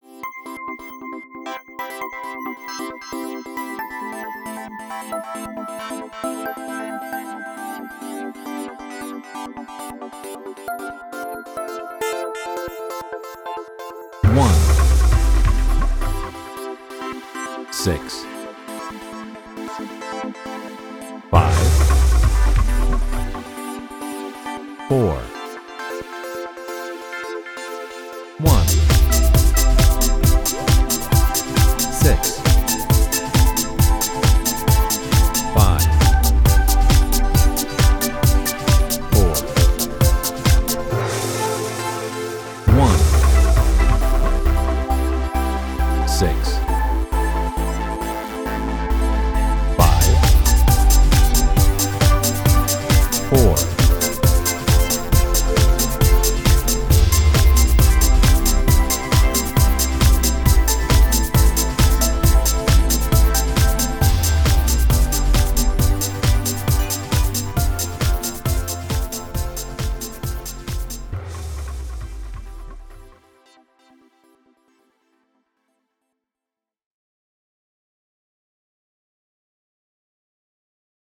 Dance
Training 4. I-vi-V-IV (C)